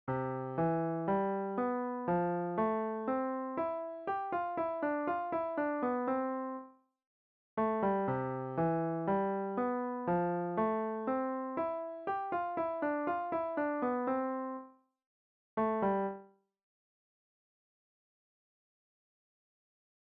Same additive process, now we've a three measure lick that closes onto beat one of the next measure.